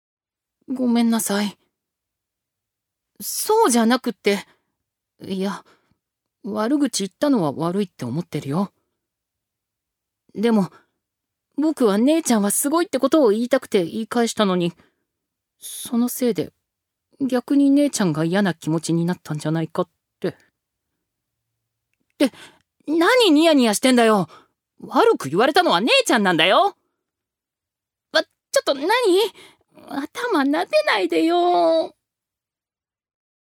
預かり：男性
セリフ３